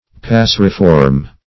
Passeriform \Pas*ser"i*form\, a.